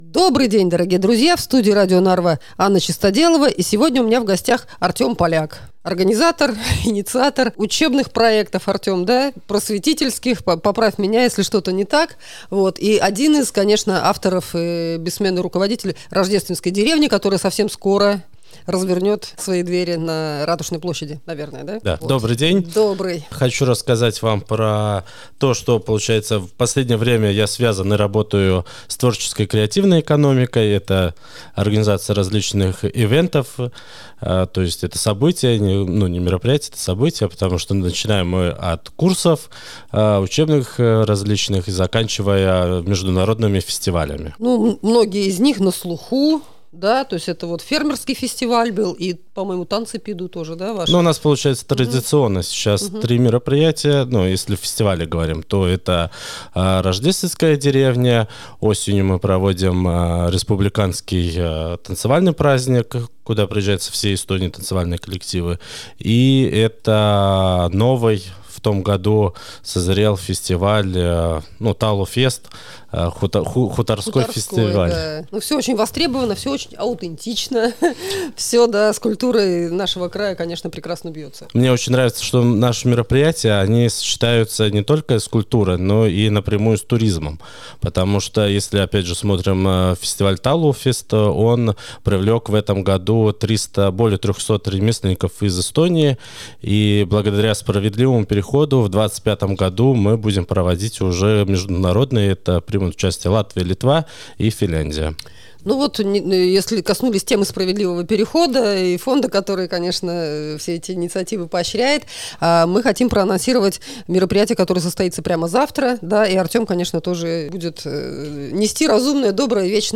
Подробности - в интервью.